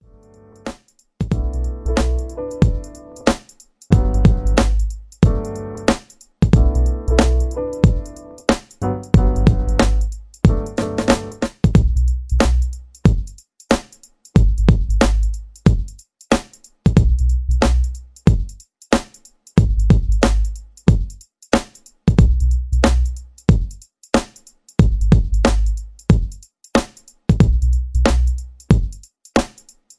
East Coast Hip Hop